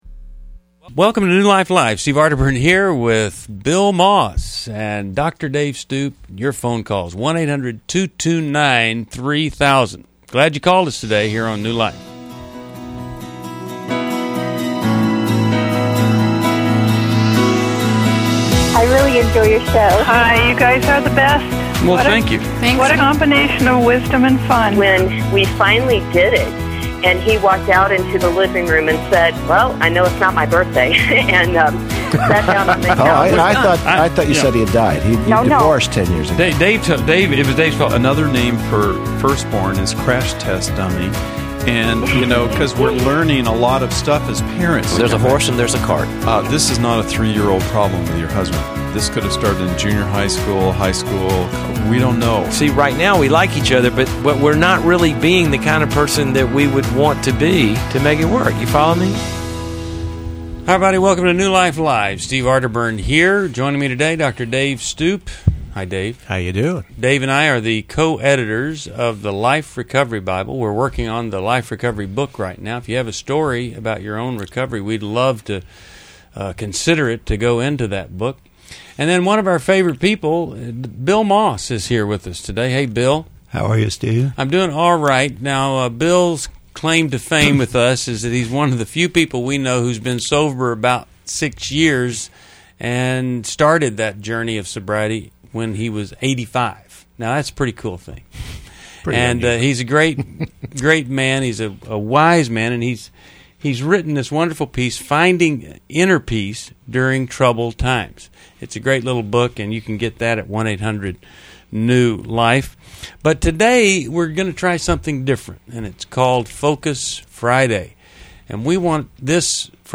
Explore addiction, anger, and recovery in this episode of New Life Live, featuring callers tackling personal struggles and seeking guidance.